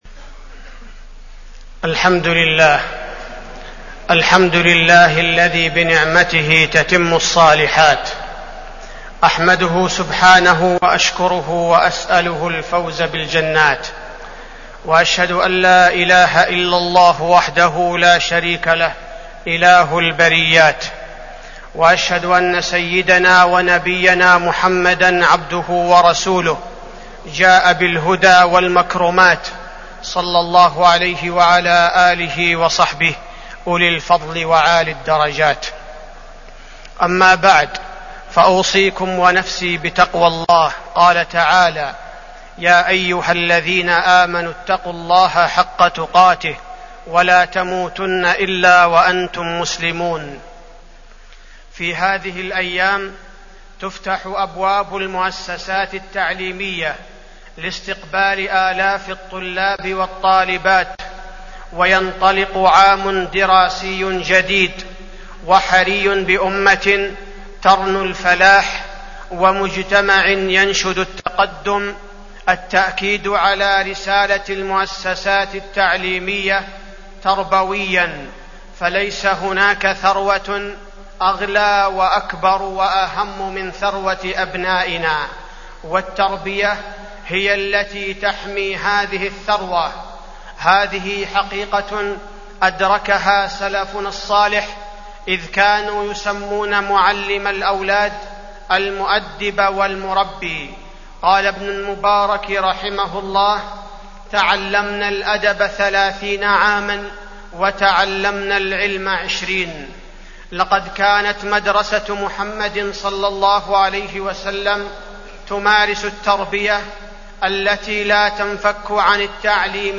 تاريخ النشر ٢٥ شعبان ١٤٢٨ هـ المكان: المسجد النبوي الشيخ: فضيلة الشيخ عبدالباري الثبيتي فضيلة الشيخ عبدالباري الثبيتي غاية التعليم التربية